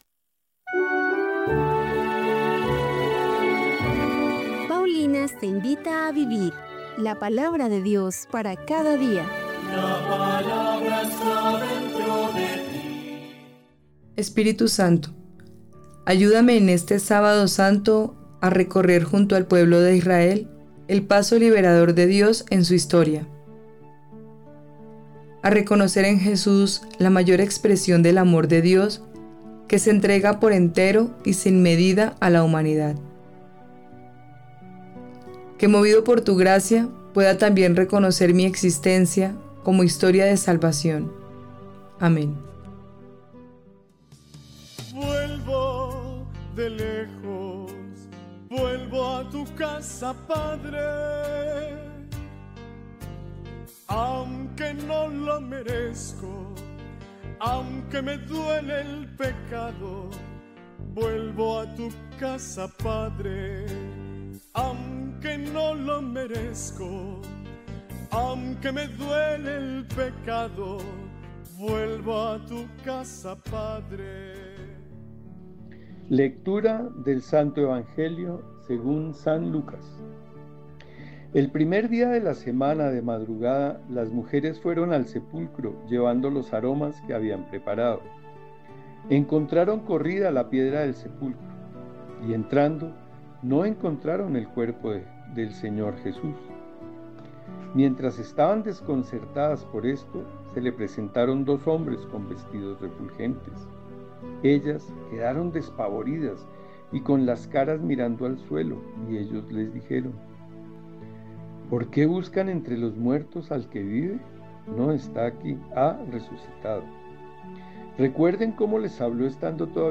Lectura de los Hechos de los Apóstoles 9, 1-20